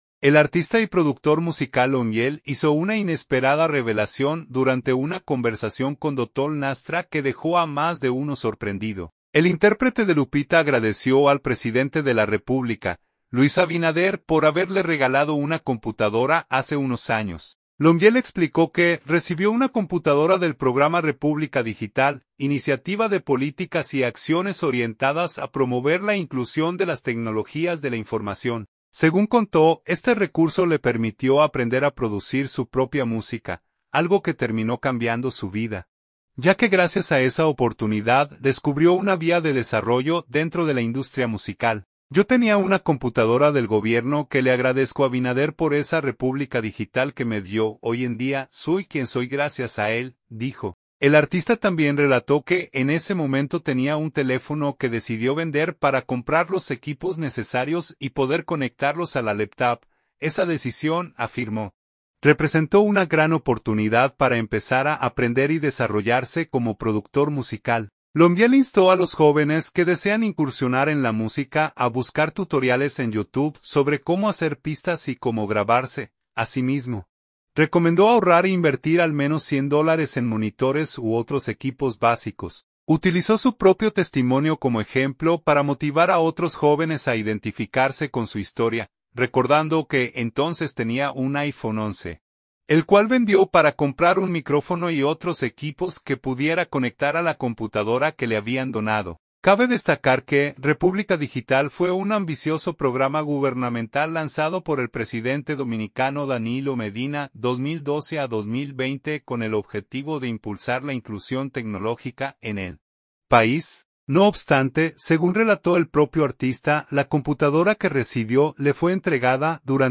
El artista y productor musical Lomiiel hizo una inesperada revelación durante una conversación con Dotol Nastra que dejó a más de uno sorprendido.